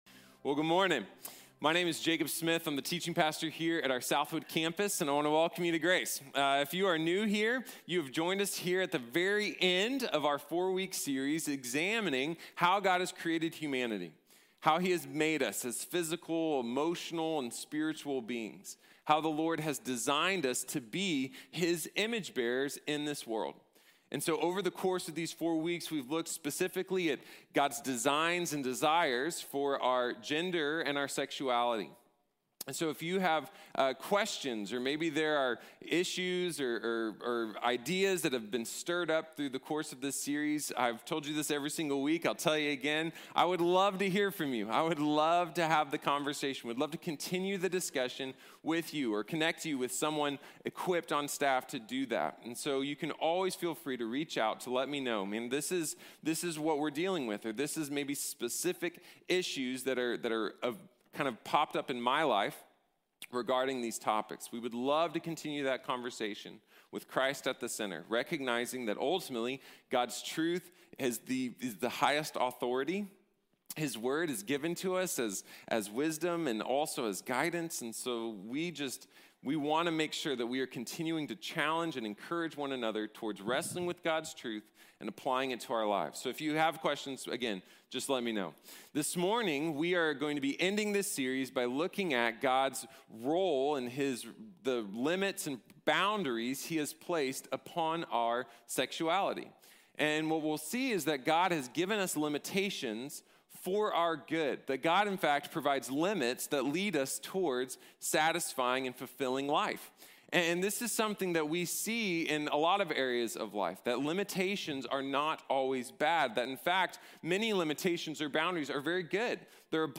Sex & Attraction | Sermon | Grace Bible Church